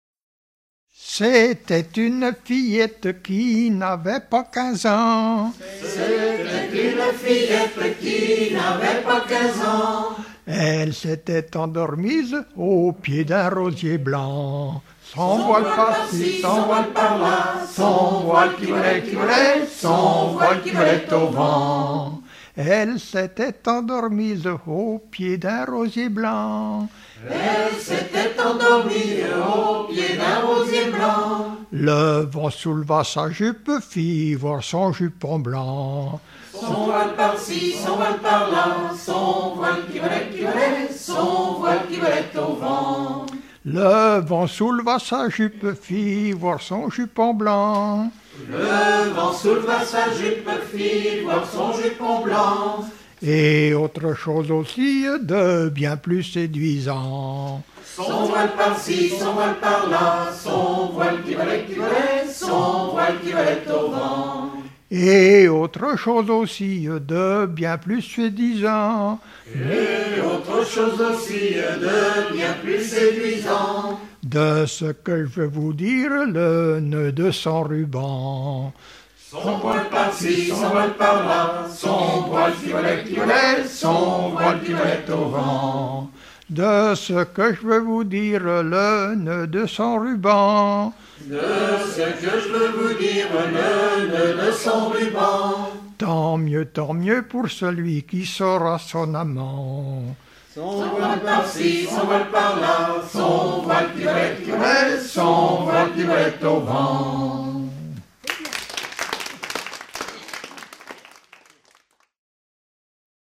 Fonction d'après l'analyste gestuel : à marcher
Genre laisse